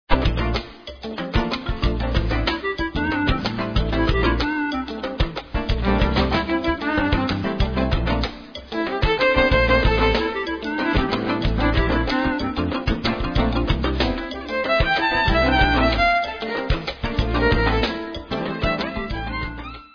West-african backing adds world music flavour "special"
sledovat novinky v oddělení Jazz/Fusion